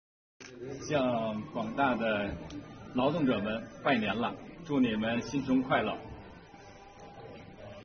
杨利伟向广大劳动者送新春祝福。